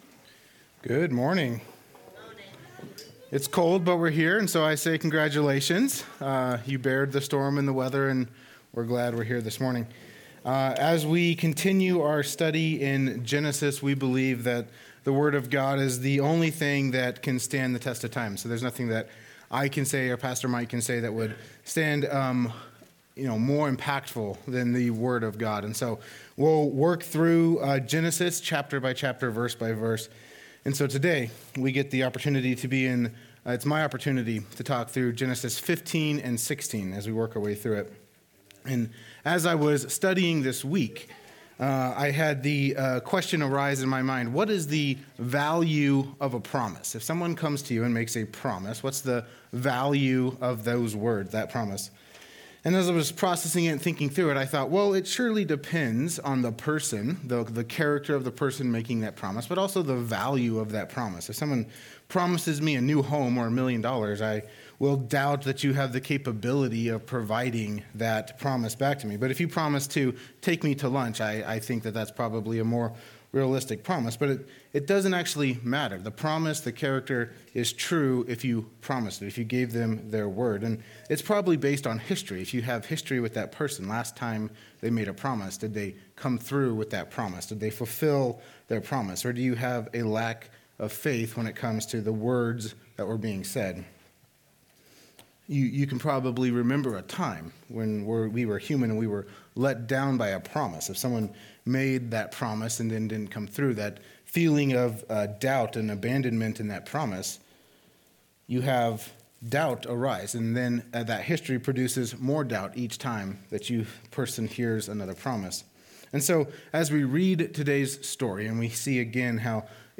Message
Location: High Plains Harvest Church